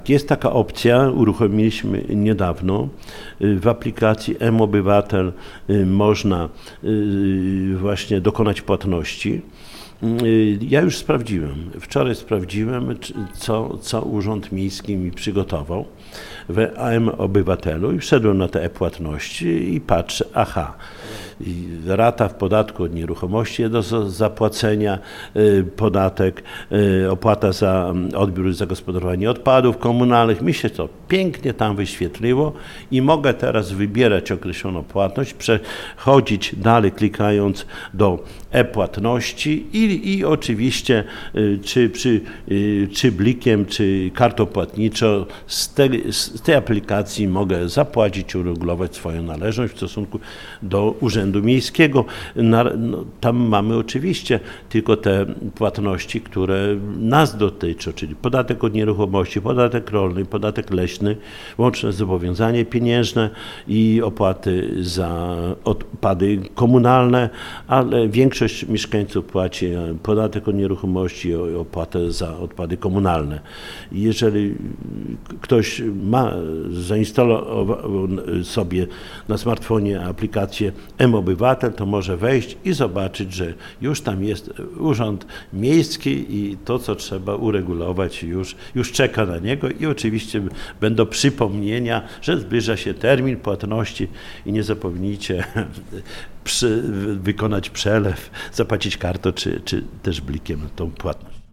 O szczegółach Czesław Renkiewicz, prezydent Suwałk: